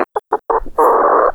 Added chicken sounds to the chickens.